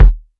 Kicks
KICK.109.NEPT.wav